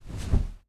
sfx_action_pillow_grab_01.wav